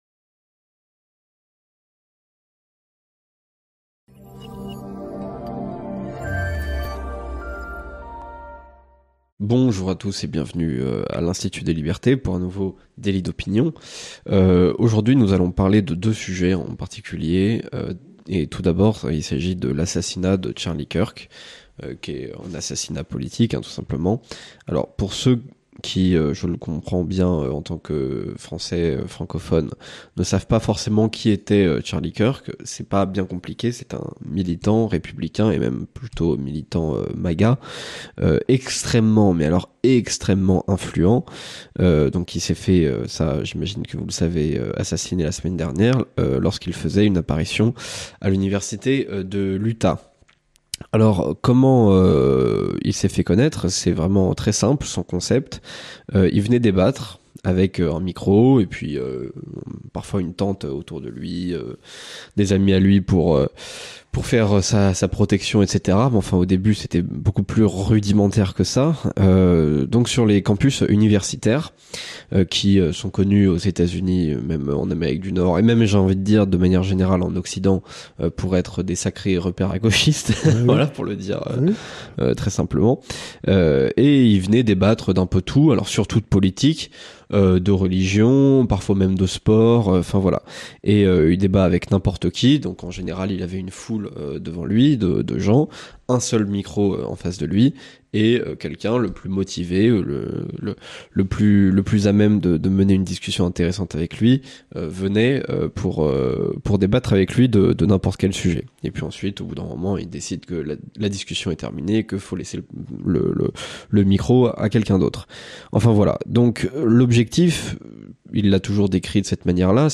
Dans l'entretien de cette semaine